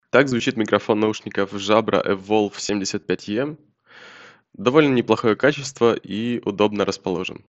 6.1 Качество микрофона
Микрофон Jabra Evolve 75e обладает неплохим качеством записи и удобно расположен возле рта.
Передает голос четко и без помех.